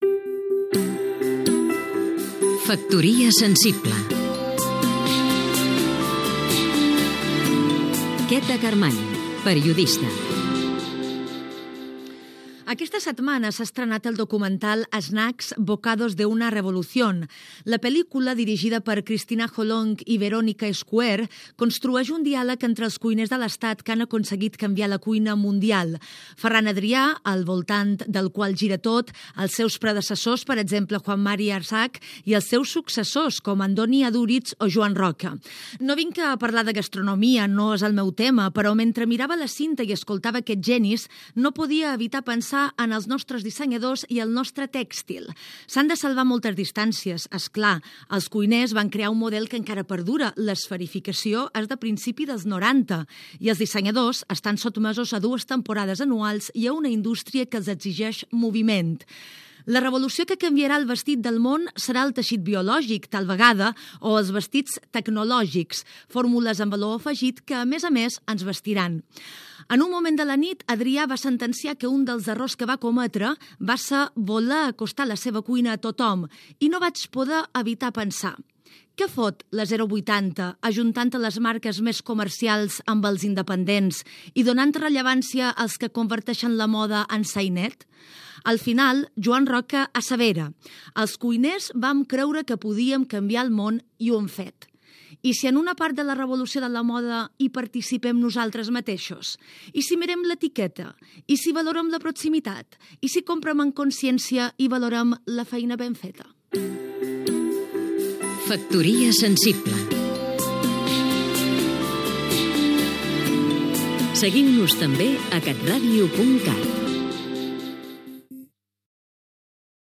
Careta del programa i comentari del documental "Snacks, bocados de una revolución" i la mostra de moda 080. Careta de sortida
Entreteniment